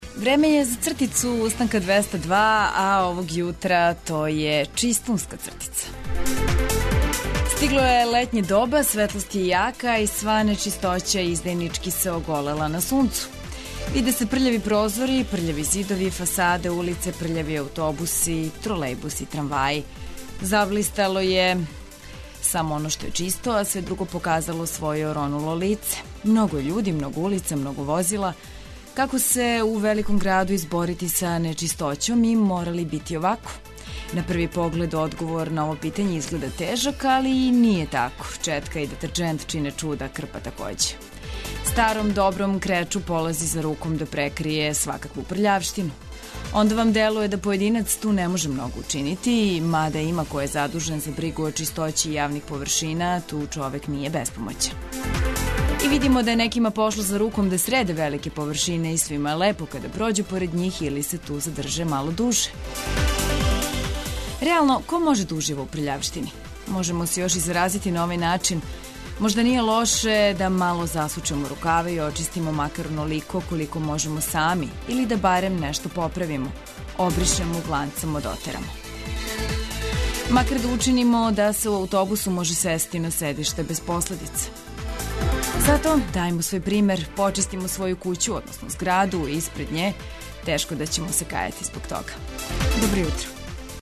Разбуђујемо се и уз уобичајене јутарње поп-рок ритмове, прелиставање домаће штампе, сервисне информације, нове вести, али и уз увек потребне детаље о стању на путевима и временској прогнози.